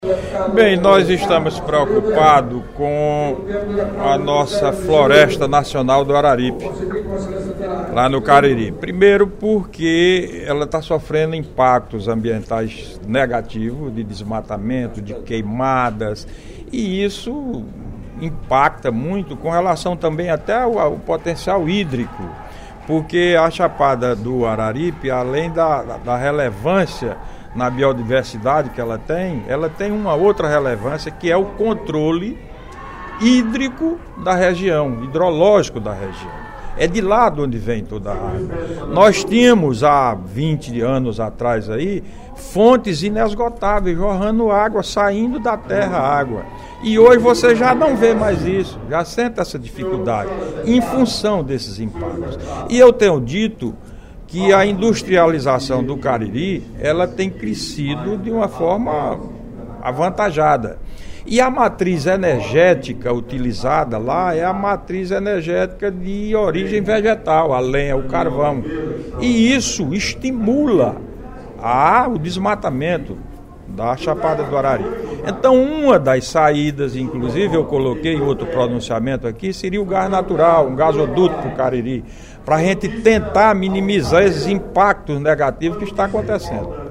A situação da Floresta Nacional do Cariri foi o assunto do deputado Vasques Landim (PR) durante o primeiro expediente da sessão plenária desta quarta-feira (12/06). O parlamentar lamentou o crescente desmatamento da região e a ausência de políticas de proteção da reserva.